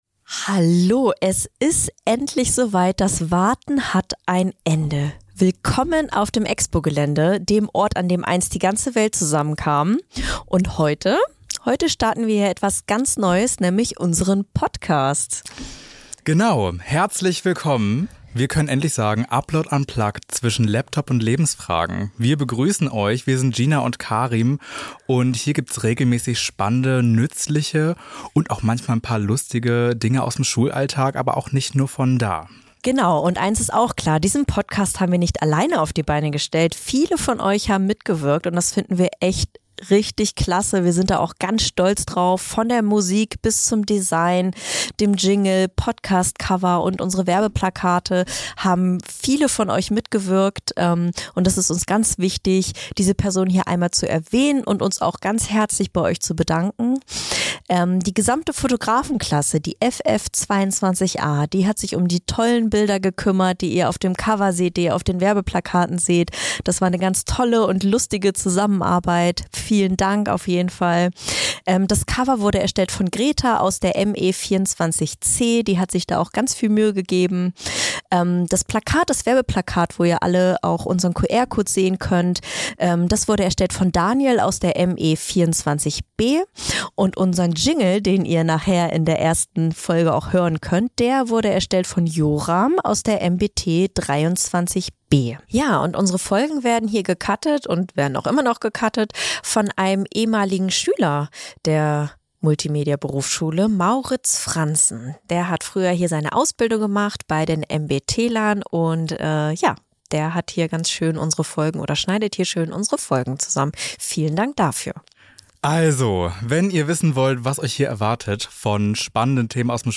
Trailer
Podcast direkt von der Expo Plaza in Hannover,